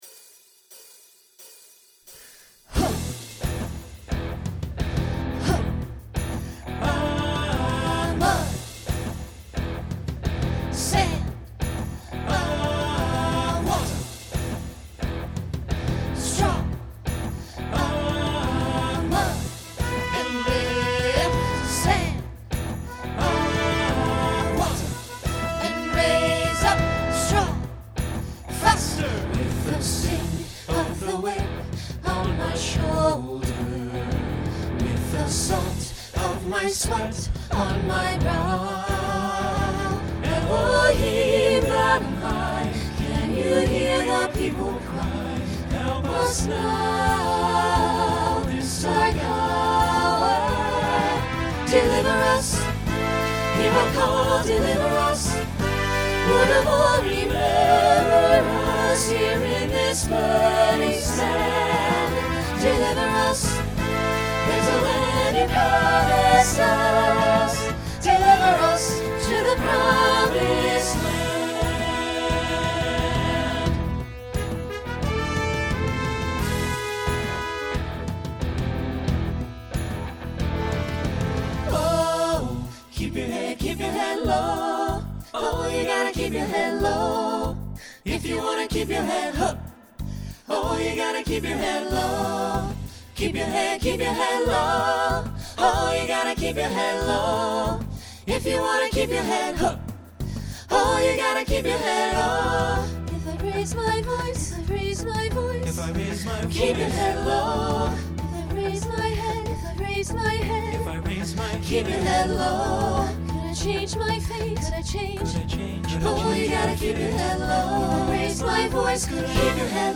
Genre Broadway/Film Instrumental combo
Voicing SATB